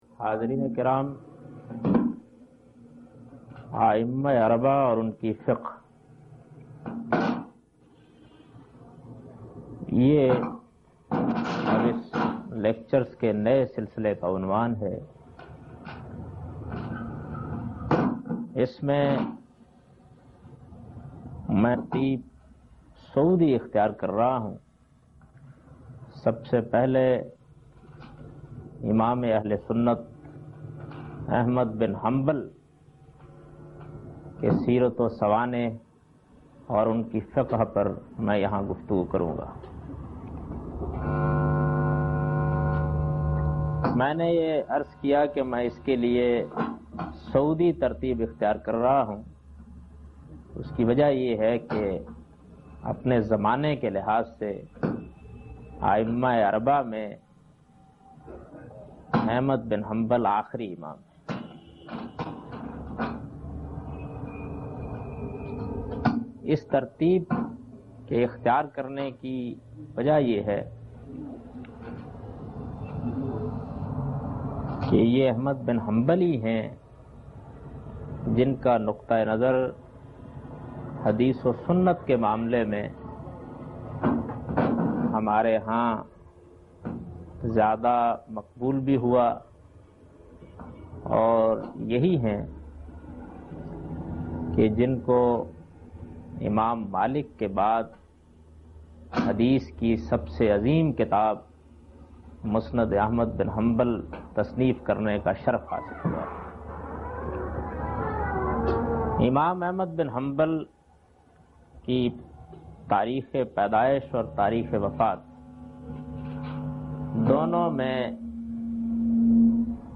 In this video Javed Ahmad Ghamidi speaks about Fiqh of Imam Ahmad Bin Hanbal.